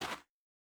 Bare Step Gravel Hard C.wav